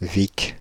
French pronunciation of « Vicques »
Fr-Paris--Vicques.ogg